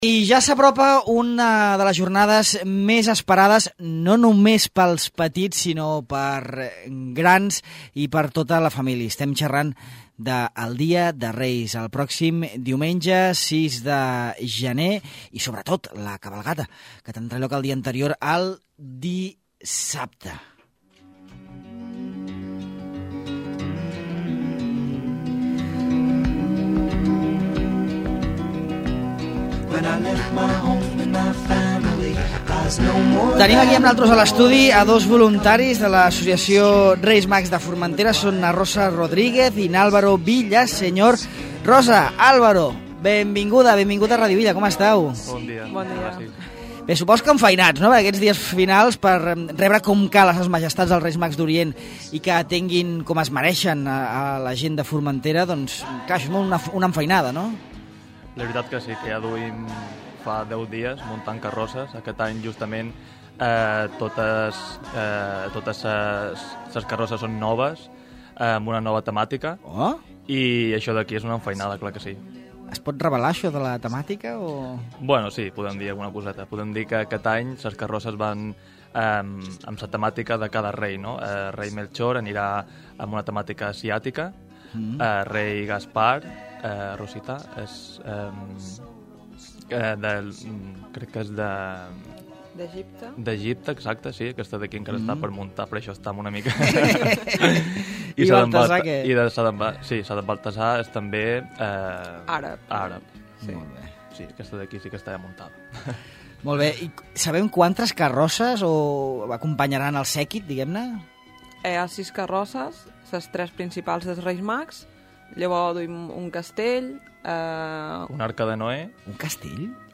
han explicat a Ràdio Illa com s’organitza i com serà l’arribada a Formentera de ses Majestats Melcior, Gaspar i Baltasar.